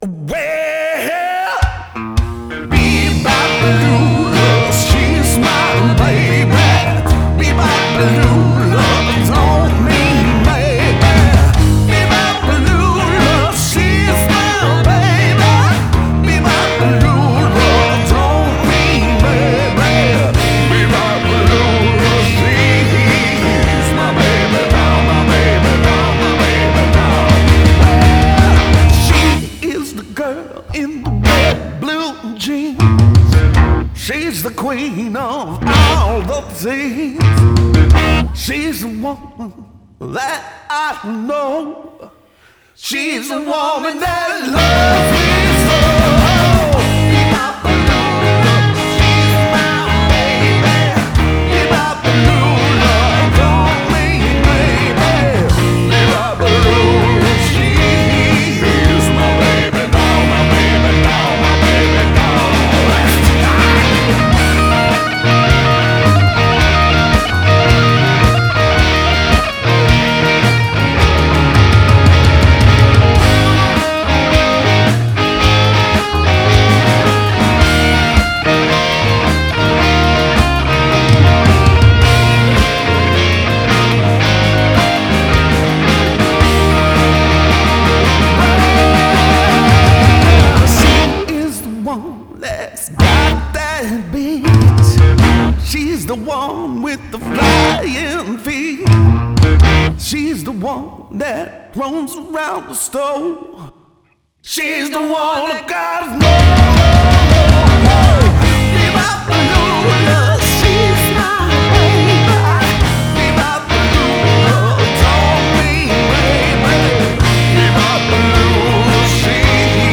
guitar + vocals
bass + vocals
drums